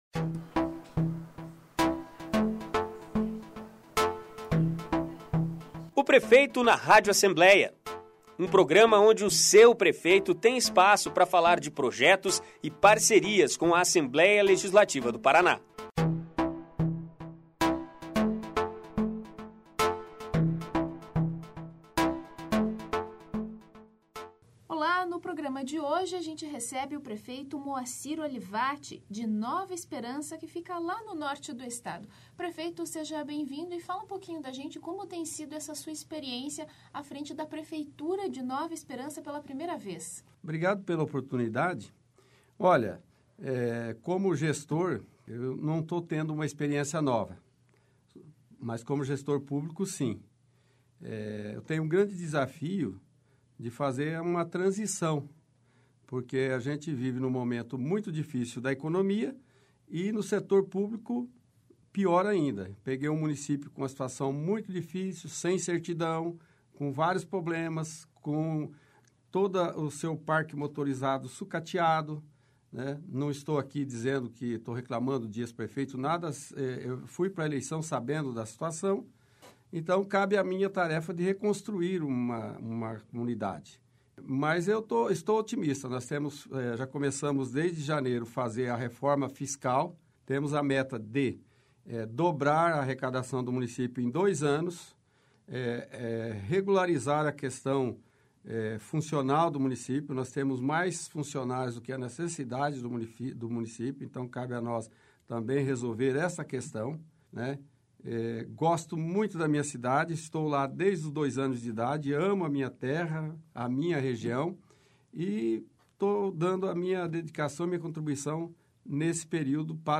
Ouça a entrevista com o prefeito Moacir Olivatti, de Nova Esperança, que está no "Prefeito na Rádio Alep" desta semana.